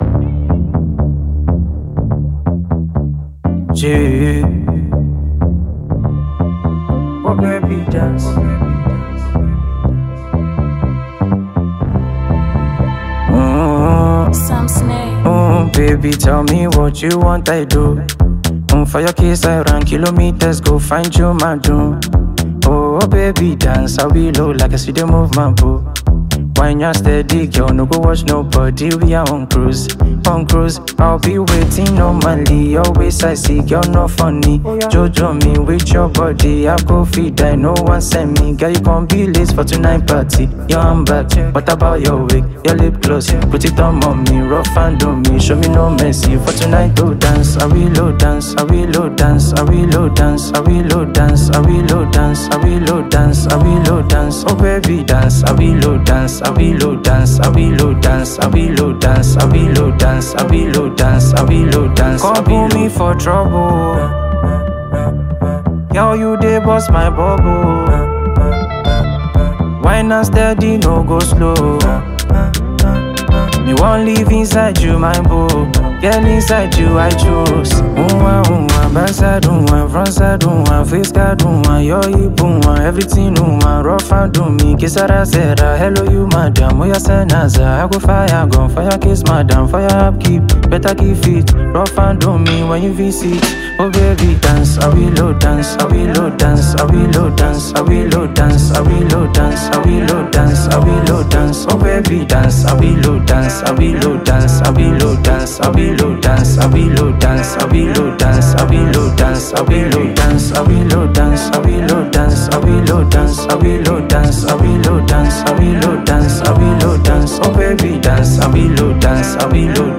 Enjoy this amazing Afrobeat production.